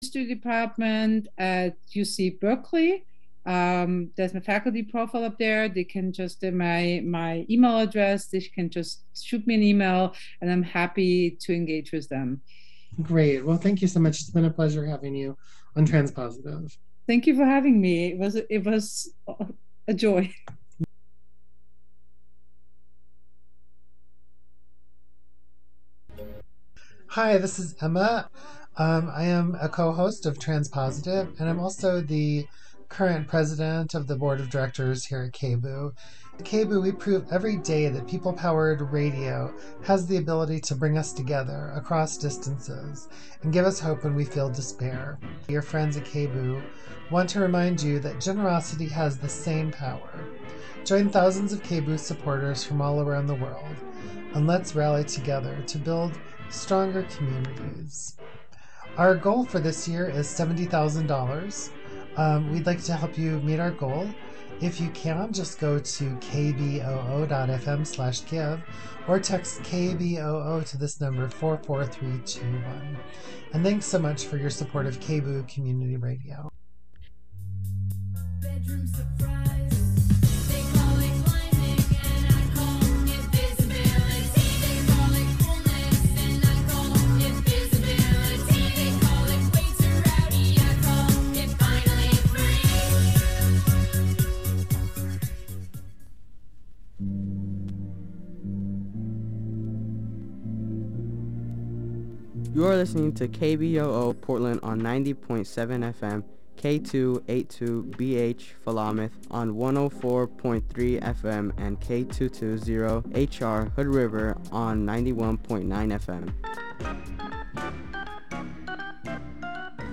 Recorded in January of 2021, this interview is a companion piece to a project called Can I Get A Witness, a collaborative transmedia project between For The Wild and Lead to Life.